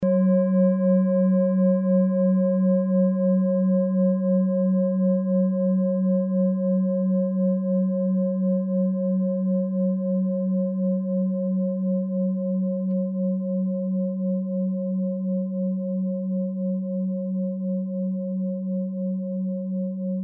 Tibet Klangschale Nr.19, Planetentonschale: Mondkulmination
Klangschalen-Gewicht: 1050g
Klangschalen-Durchmesser: 20,1cm
(Ermittelt mit dem Filzklöppel oder Gummikernschlegel)
Die Klangschale hat bei 185.54 Hz einen Teilton mit einer
Die Klangschale hat bei 188.47 Hz einen Teilton mit einer
klangschale-tibet-19.mp3